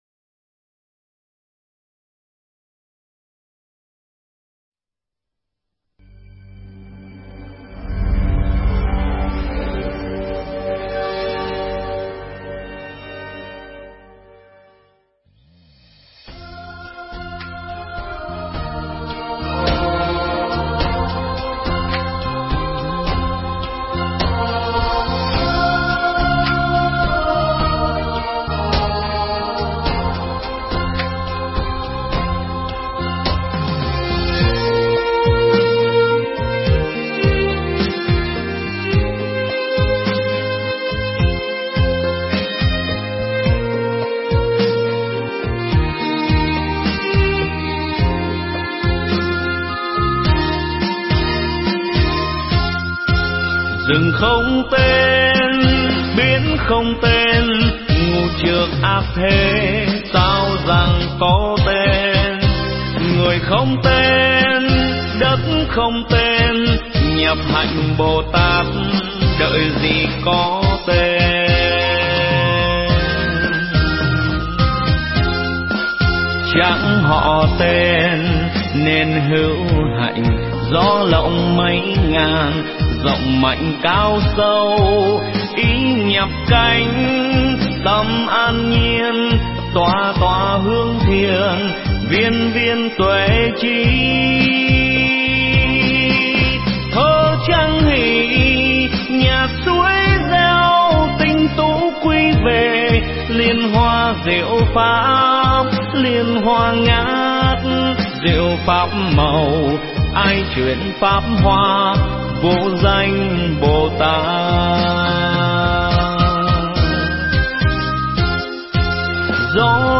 Nghe Mp3 thuyết pháp Người Nội Trợ Tu Bồ Tát Đạo